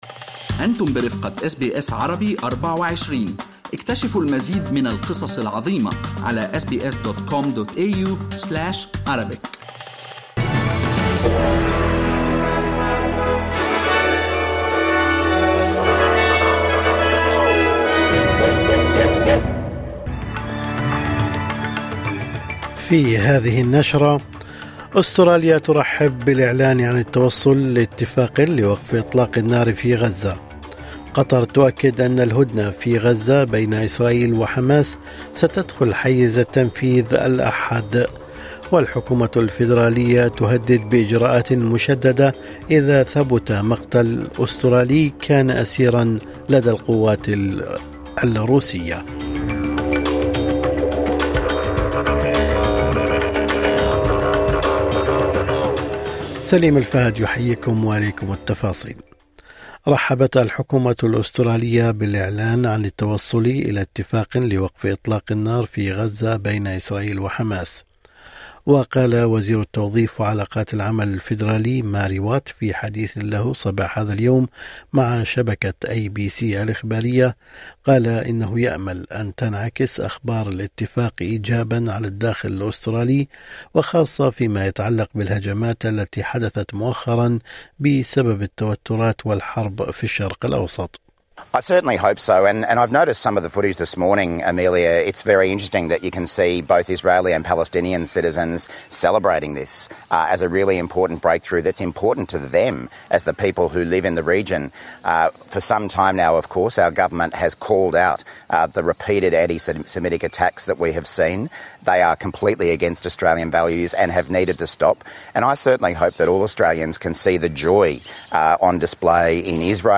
نشرة أخبار الصباح 16/1/2025